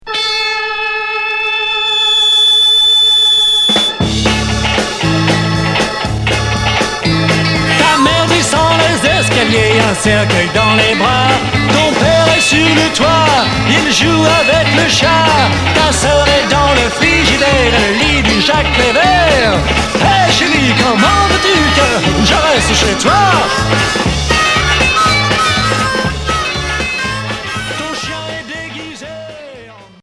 Rock garage